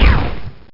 FIREBALL.mp3